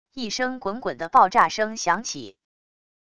一声滚滚的爆炸声响起wav音频